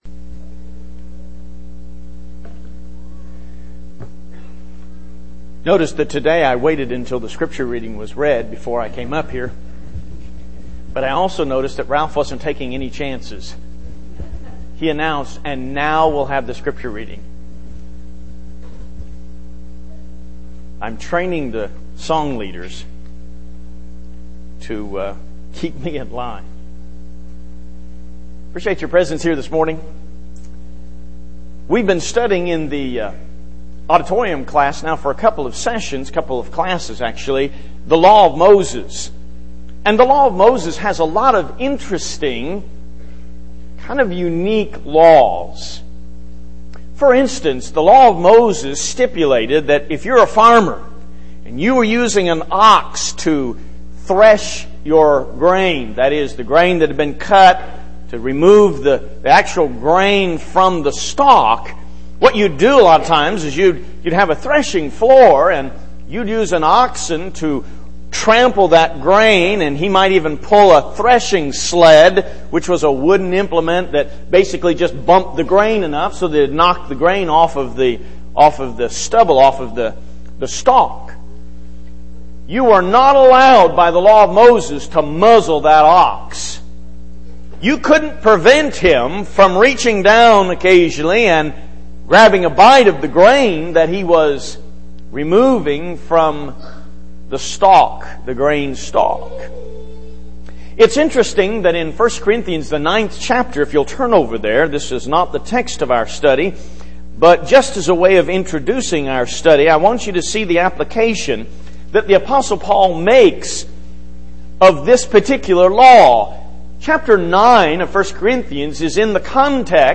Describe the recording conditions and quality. Service: Sun Bible Study Type: Sermon